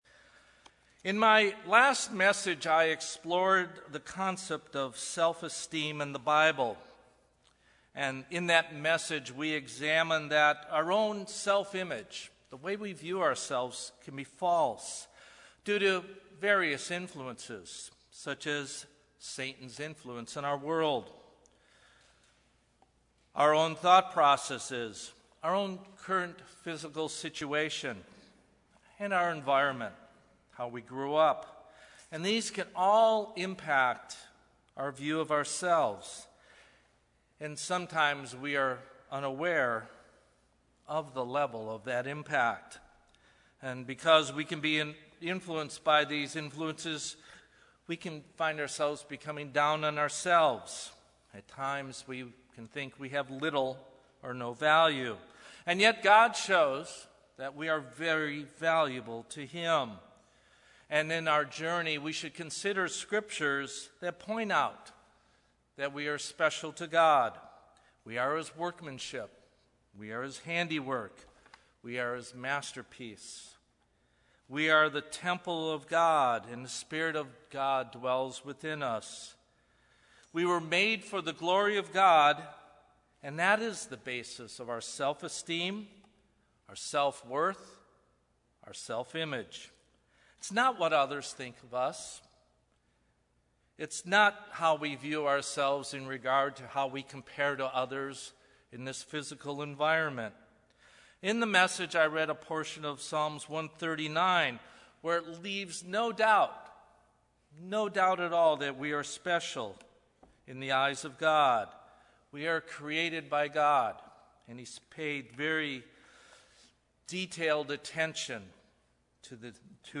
Sermons
Given in Los Angeles, CA